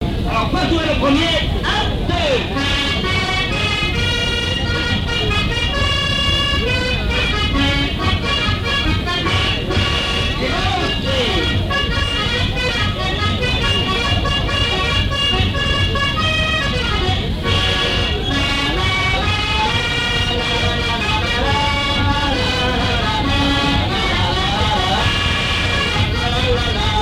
Mémoires et Patrimoines vivants - RaddO est une base de données d'archives iconographiques et sonores.
danse : quadrille : pastourelle
lors d'une kermesse
Pièce musicale inédite